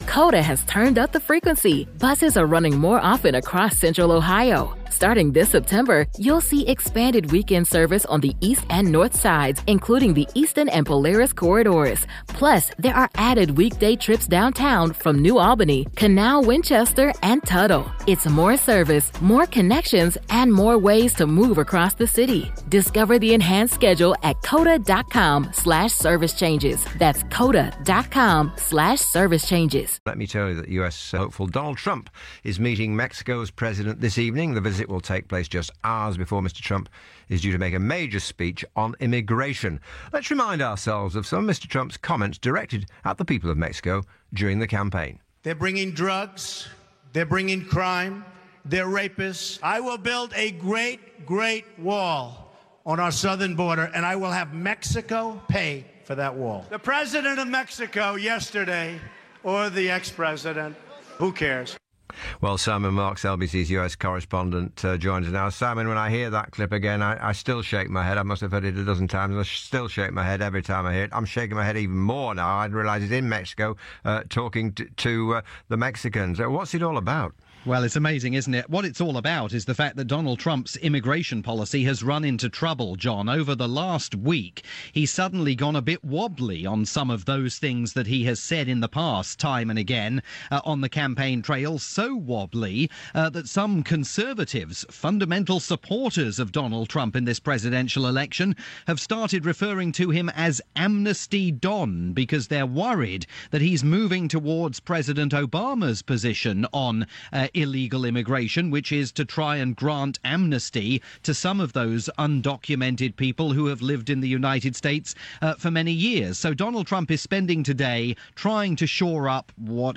reported both stories for LBC in the UK, with host John Stapleton.